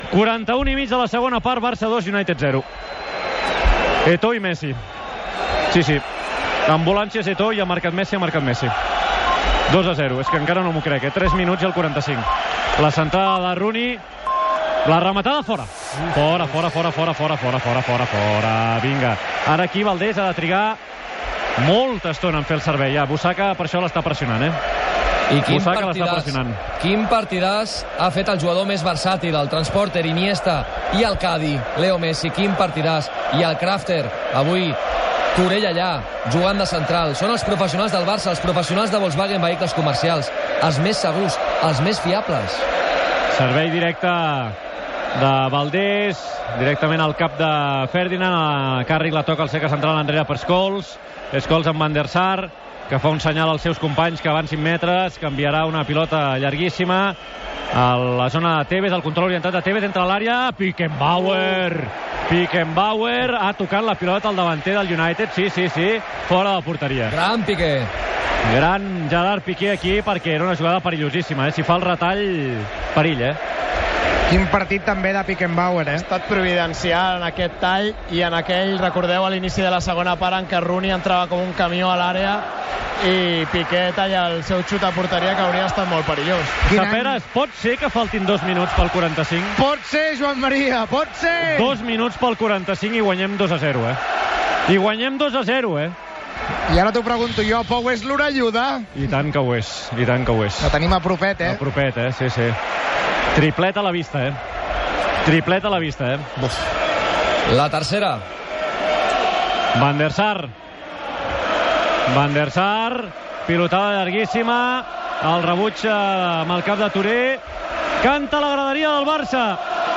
Transmissió de la final de la Lliga masculina de Campions de la UEFA de 2009, des de l'estadi Olímpic de Roma. Descripció de la sortida dels jugadors, alineacions, ambient, narració de les primeres jugades i anàlisi tècnica d'algunes. Minut i resultat, narració dels últims minuts del partit.